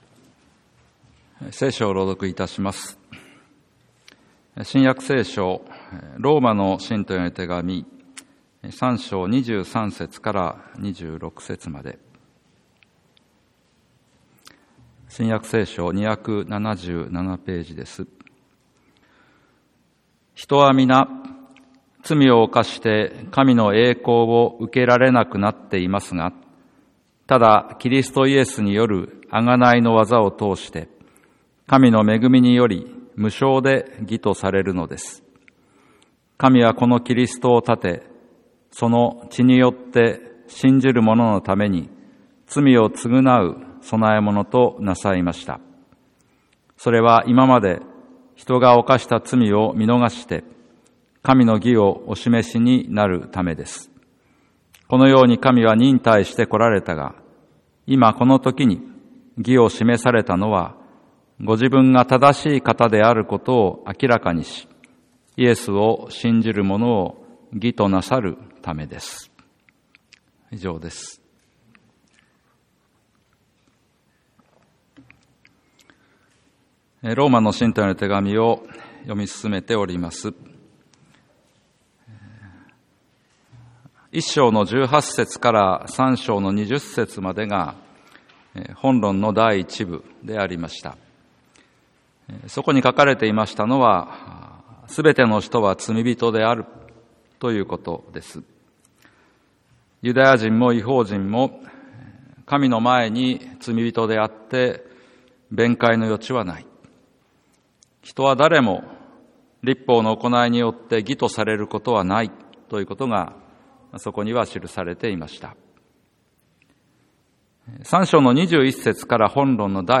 2023年12月17日 朝の礼拝